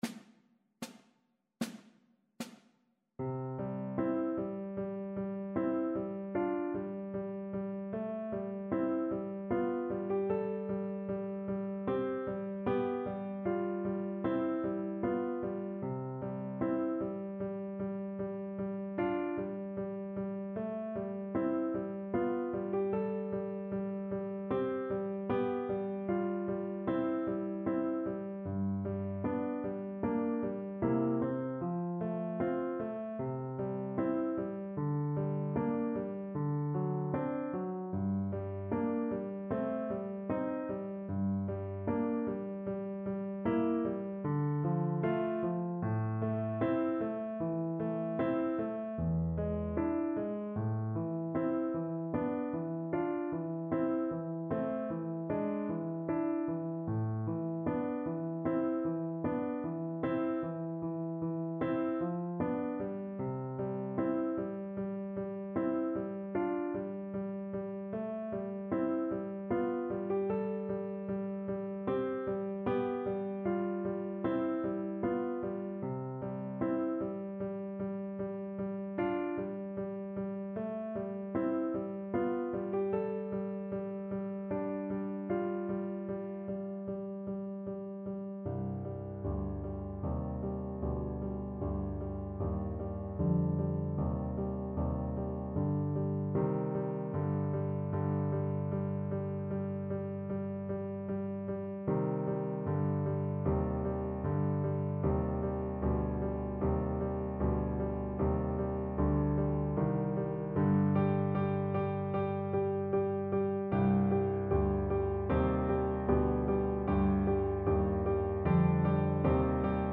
Symulacja akompaniamentu
chopin_preludium-deszczowe_nuty_vc-pf-acc..mp3